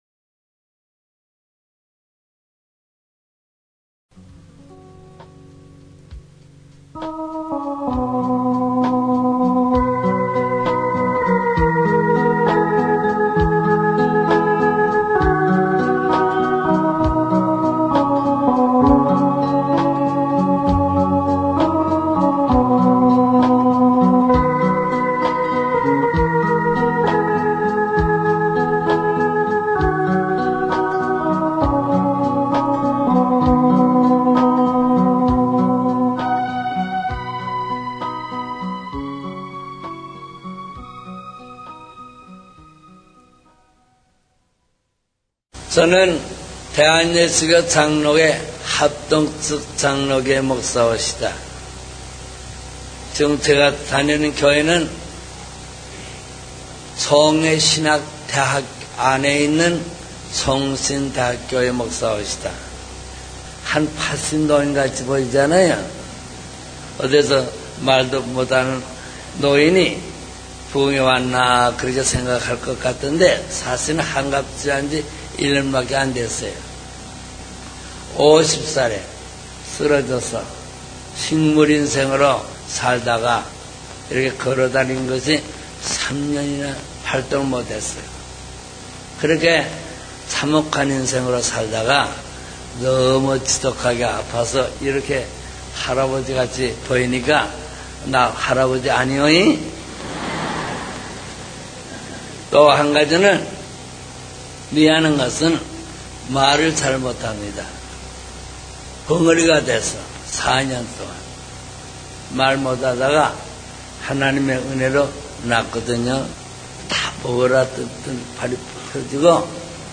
간증